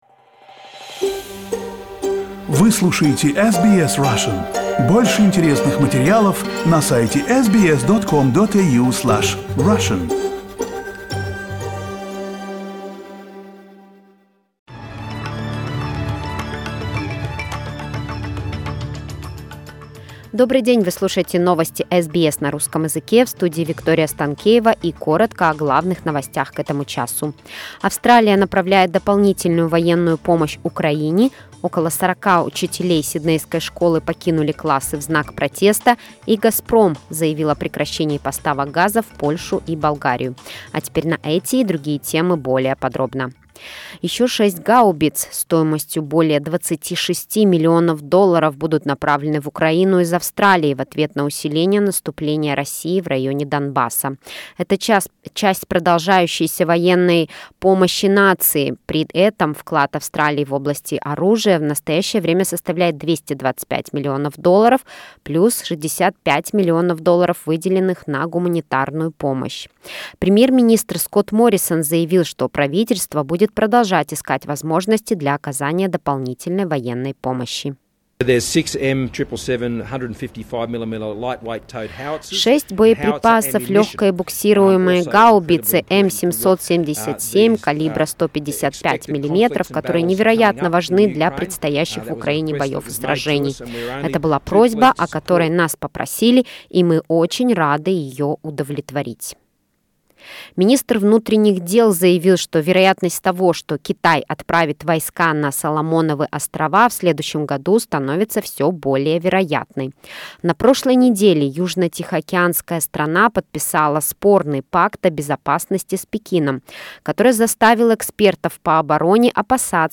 SBS news in Russian — 27.04.2022